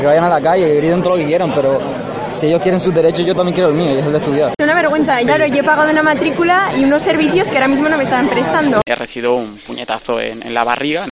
Estudiantes molestos con el bloqueo